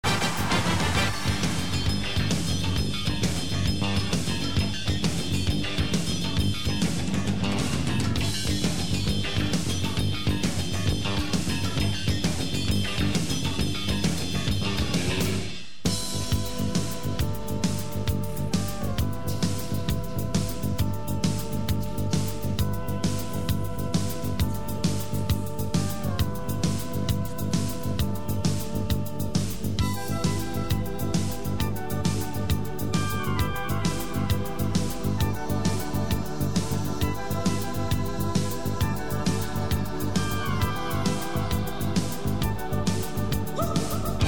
変拍子が基本。変な曲調なのに、さらりと聴かせてしまうところは凄い。